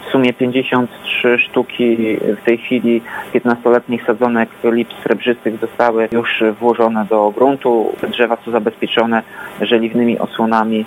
Jak dodaje prezydent Ełku, prace przy nasadzeniach dobiegają już końca.